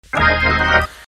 jesus organ Meme Sound Effect
jesus organ.mp3